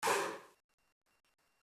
SouthSide Chant (8).wav